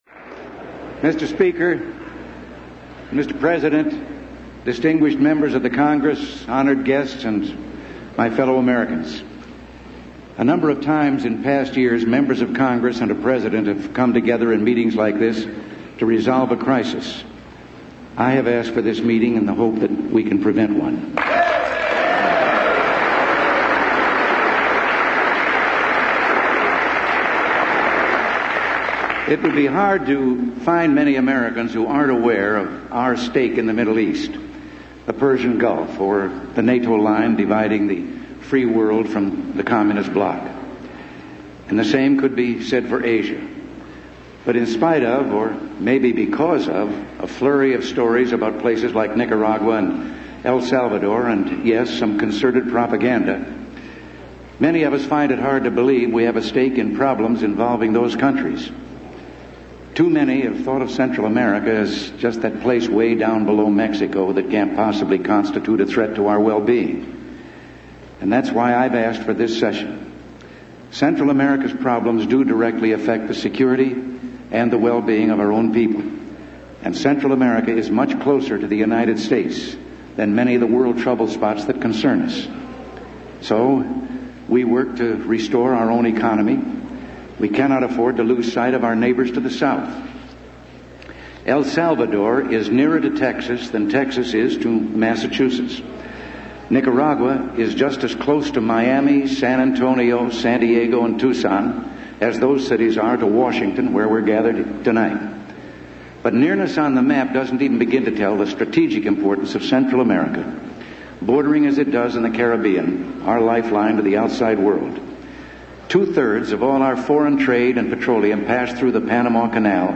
U.S. President Ronald Reagan addresses a joint session of Congress